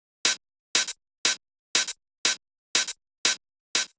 31 Hihat.wav